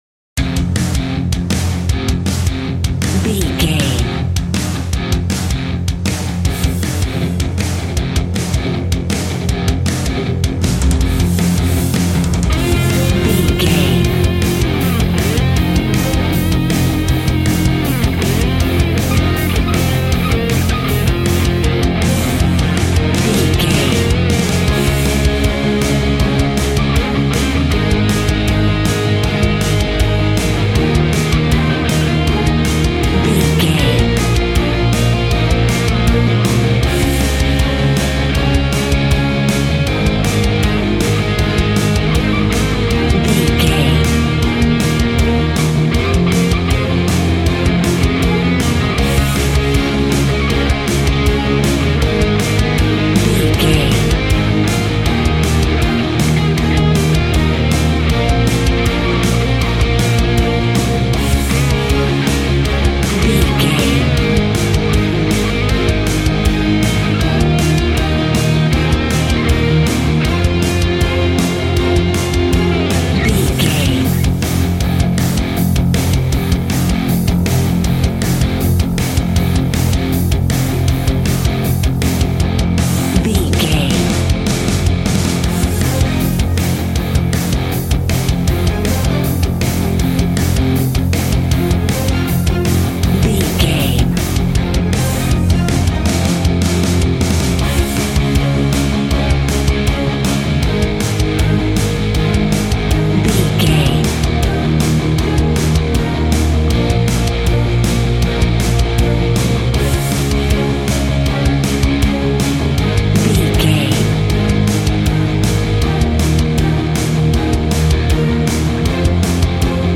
Ionian/Major
angry
heavy
aggressive
electric guitar
drums
bass guitar